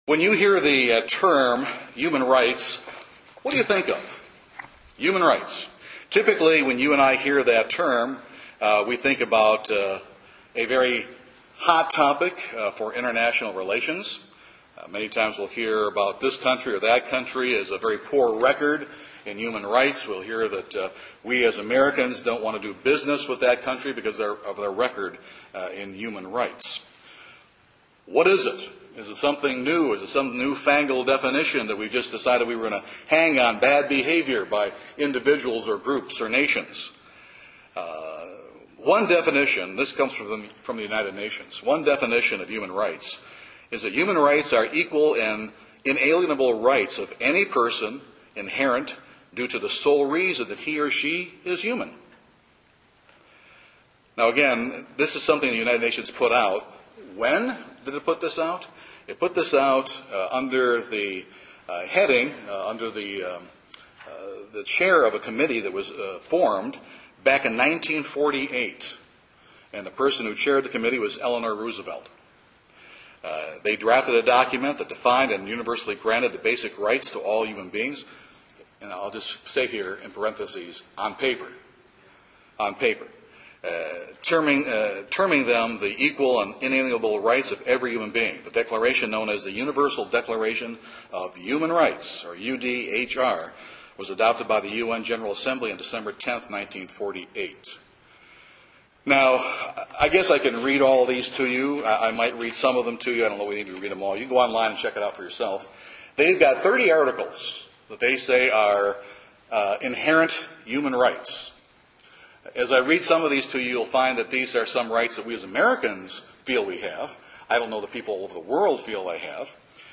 This sermon looks at basic principles found in Deuteronomy 21 that speak to the sanctity of life, respect for women, justice, and dealing with young people who pose a threat to themselves and all of society.